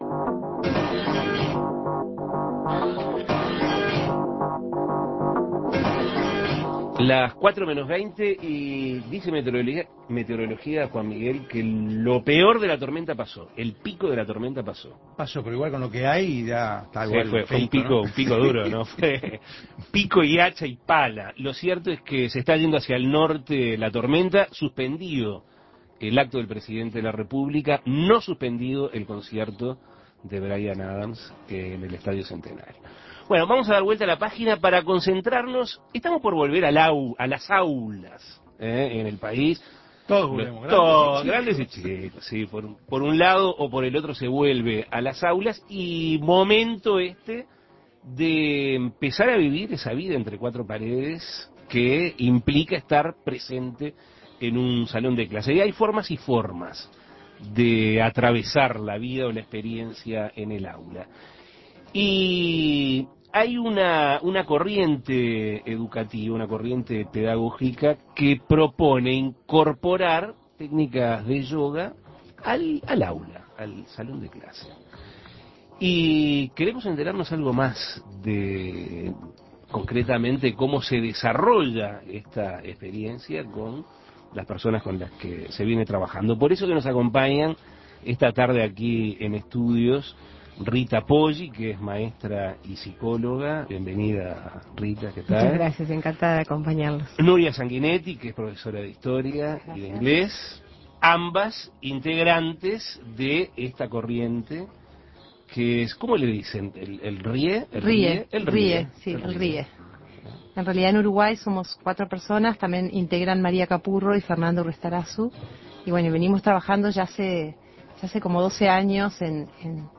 Entrevistas Yoga en las aulas Imprimir A- A A+ Una corriente pedagógica denominada RYE propone la enseñanza de yoga en las aulas.